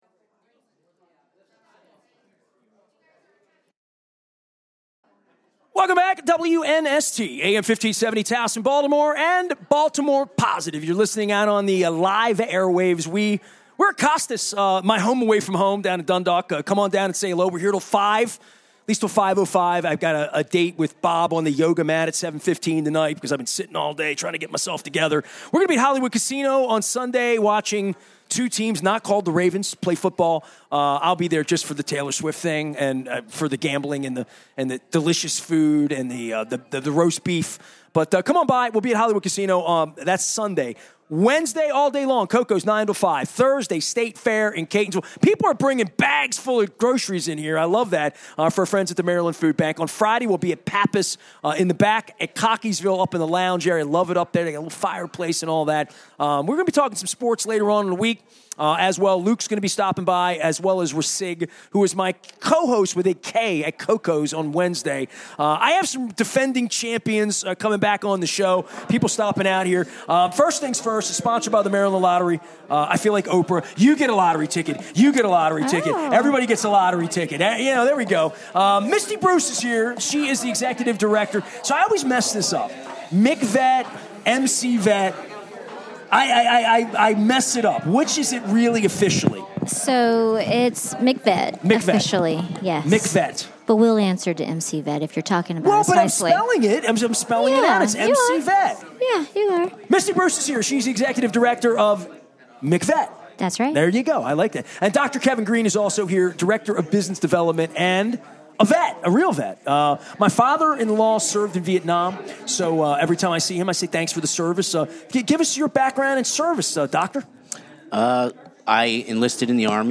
at Costas Inn